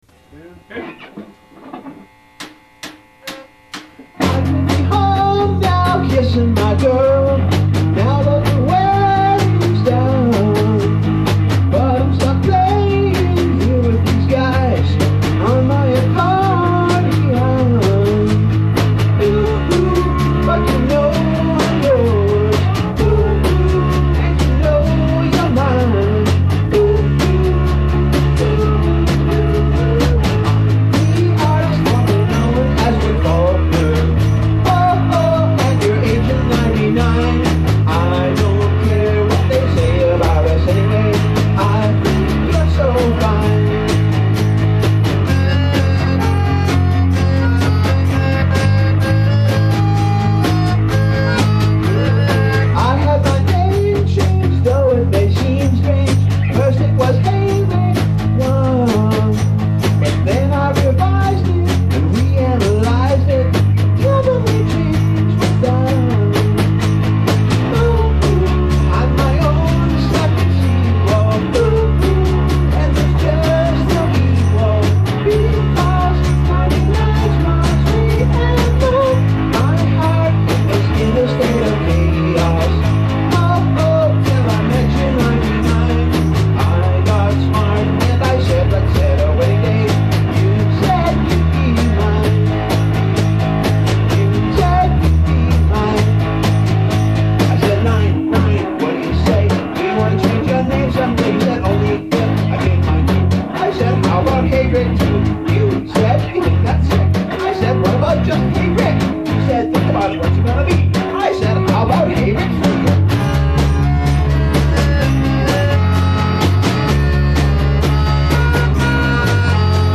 This recording was made at the same time as “The Living Room”, and again wasn’t multi-tracked or edited or anything – just two live takes, and this is the second take.